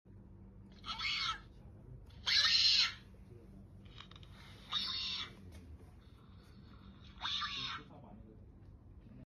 ✨Lulu the screaming hyrax✨With built sound effects free download